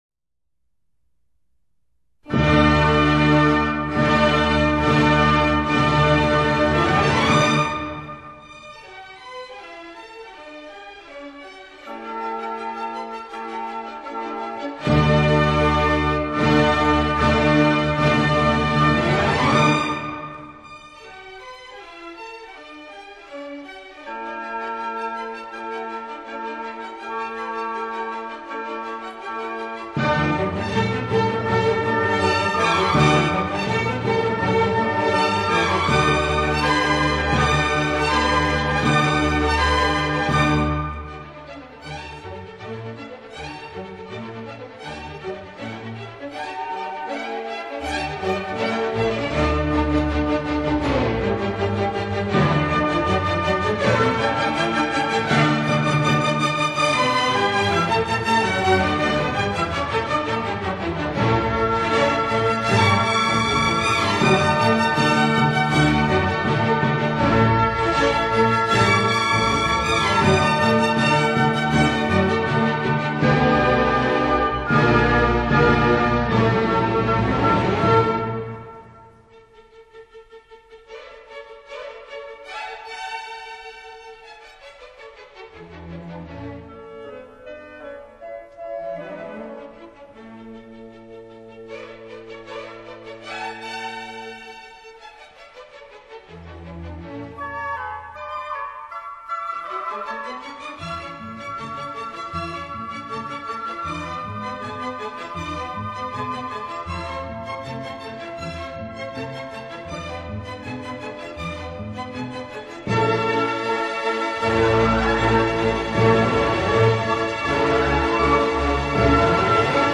虽然听上去有些过于厚重，但却十分大气